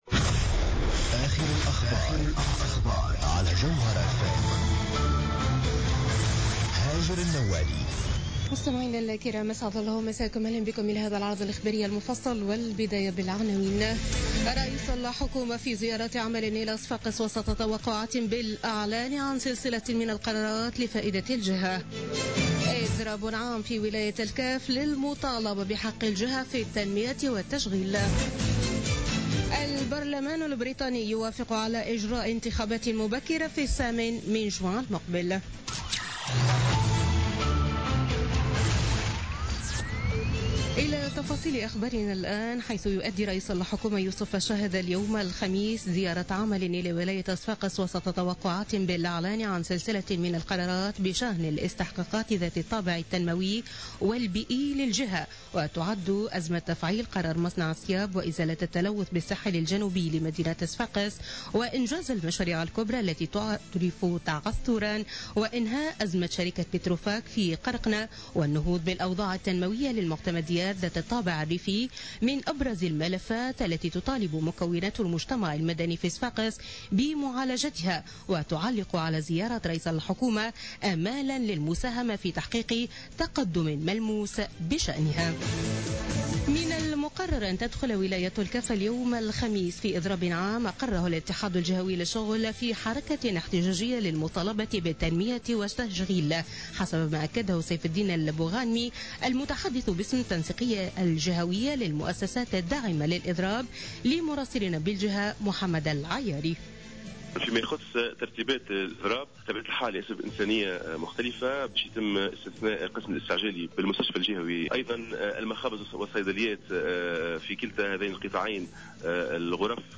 نشرة أخبار منتصف الليل ليوم الخميس 20 أفريل 2017